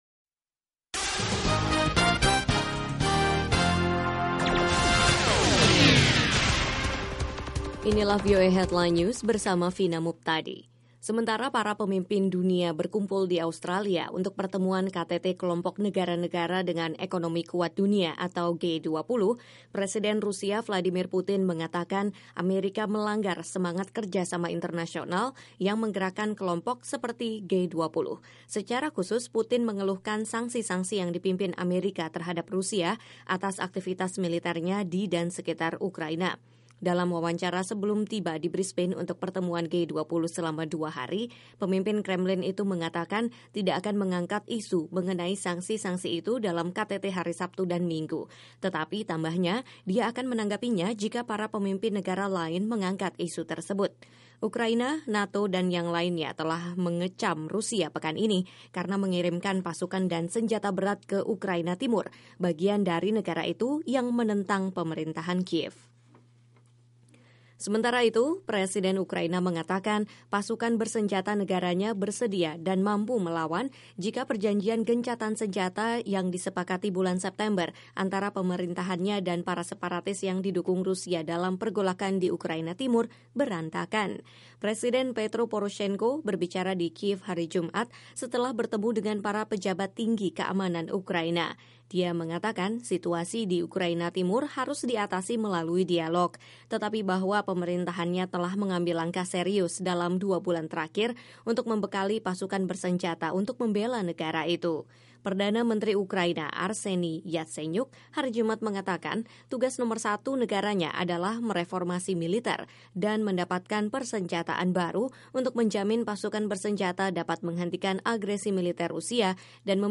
Simak berita terkini setiap 30 menit langsung dari Washington dalam Headline News, bersama para penyiar VOA yang setia menghadirkan perkembangan terakhir berita-berita internasional.